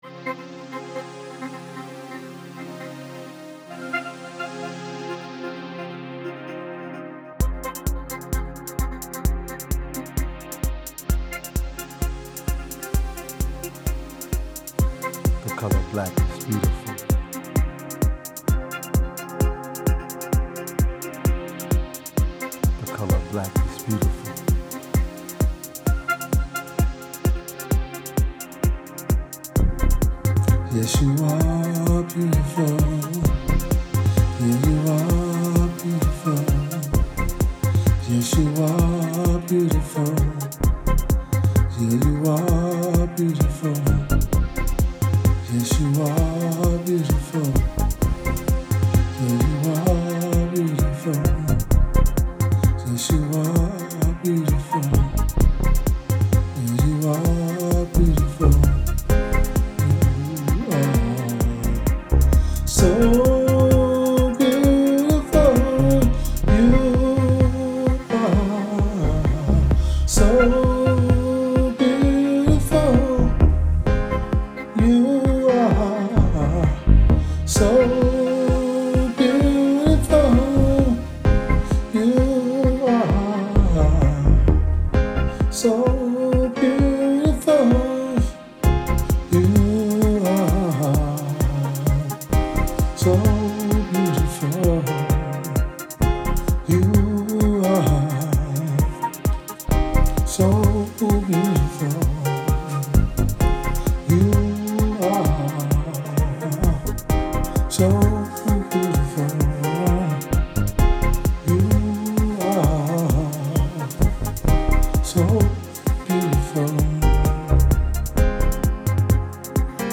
I’ve recorded a French horn track on a keyboard and would like a real saxophone.